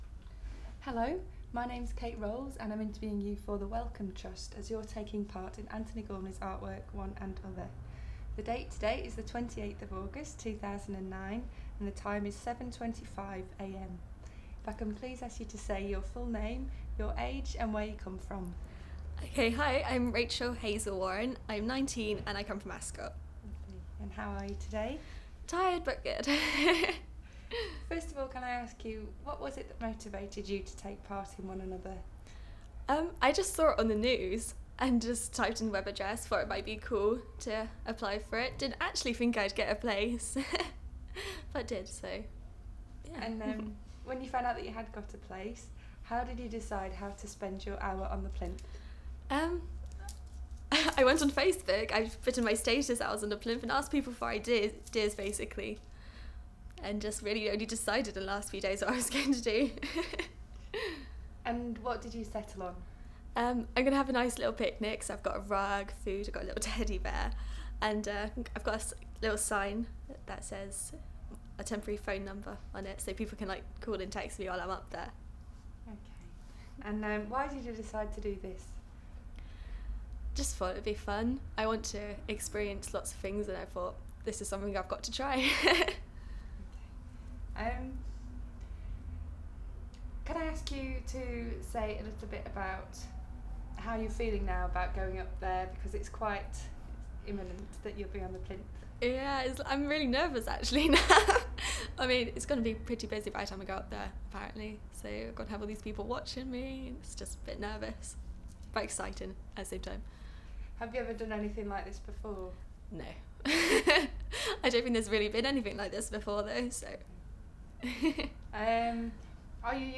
Audio file duration: 00:05:44 Format of original recording: wav 44.1 khz 16 bit ZOOM digital recorder.